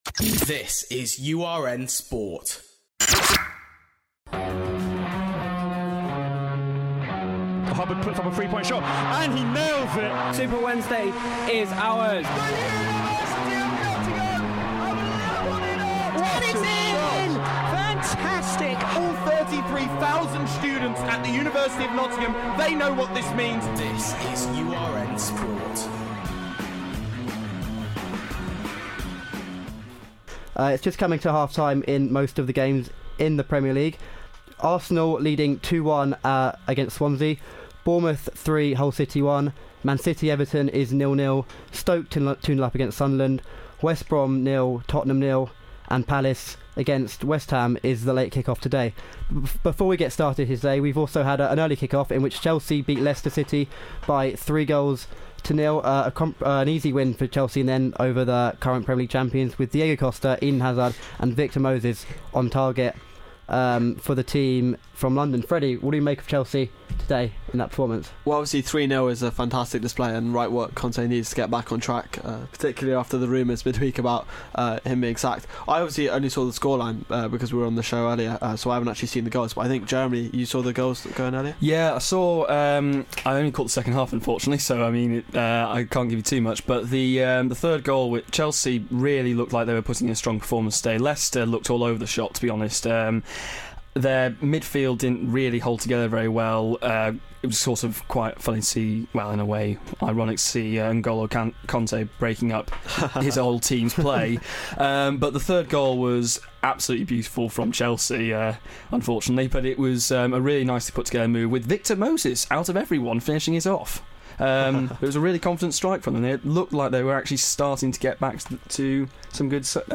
provided live updates and discussion on Saturday's Premier League matches.